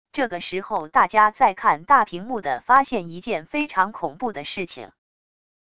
这个时候大家在看大屏幕的发现一件非常恐怖的事情wav音频生成系统WAV Audio Player